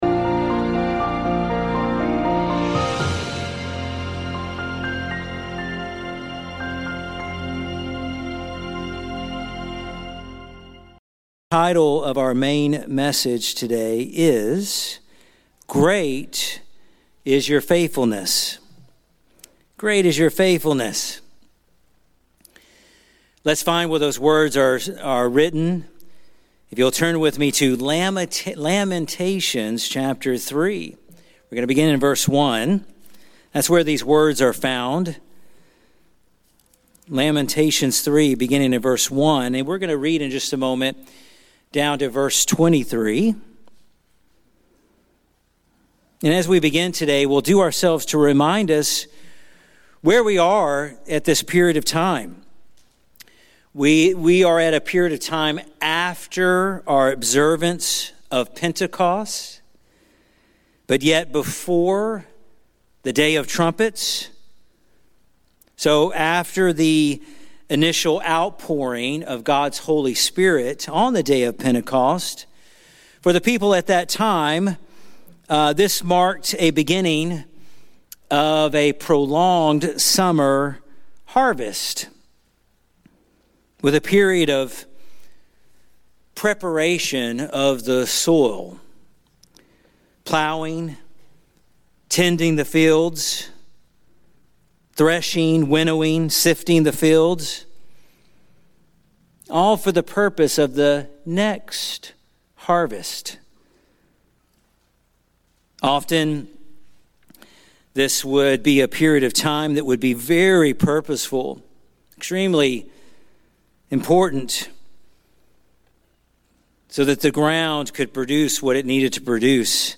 In the midst of life’s darkest lament, this sermon reminds us that God’s faithfulness shines most clearly when circumstances feel unbearable. Like Jeremiah, we learn to “call to mind” not what we feel, but who God is.